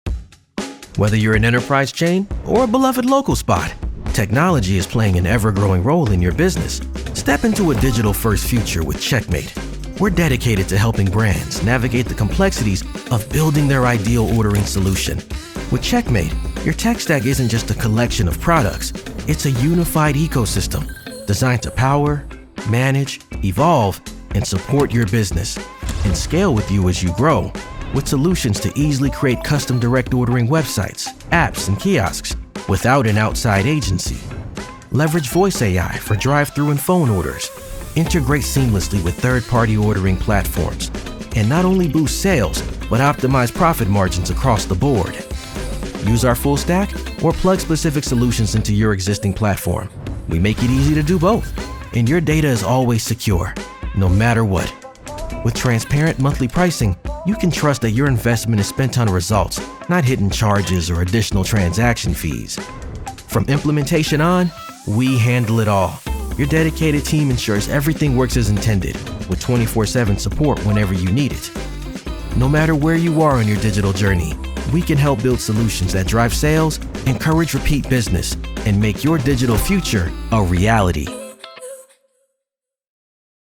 Commercieel, Stedelijk, Stoer, Vriendelijk, Warm, Natuurlijk
Corporate
Explainer
If you’re looking for a grounded, believable male voice for your project, look no further!